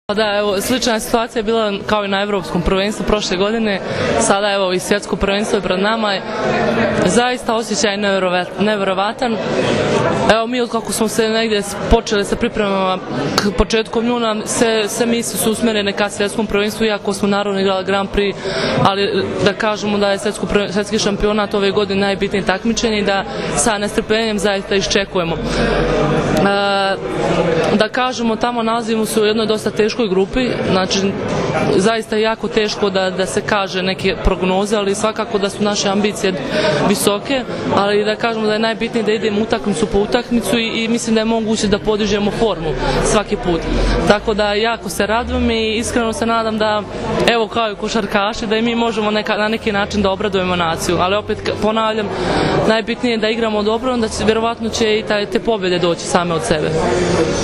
IZJAVA BRANKICE MIHAJLOVIĆ